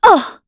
voices / heroes
Isolet-Vox_Damage_kr_01.wav